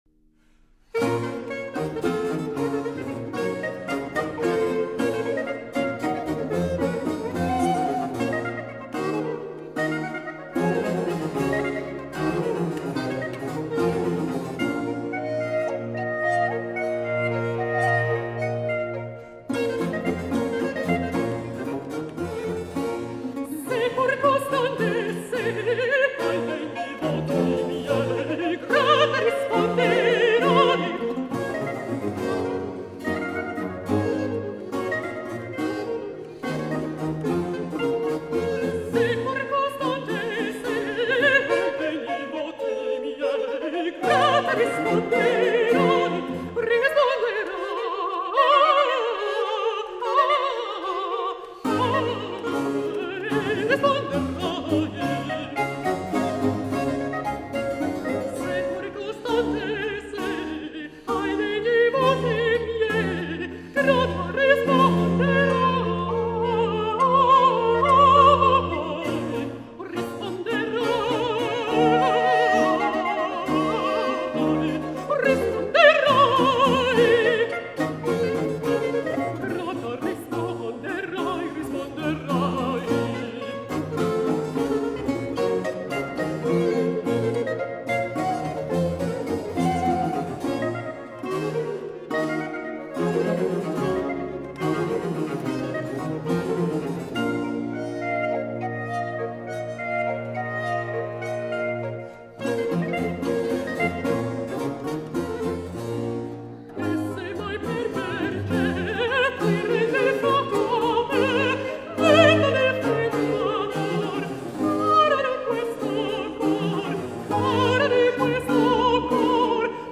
Italian Baroque
vibrant countertenor voice
the final aria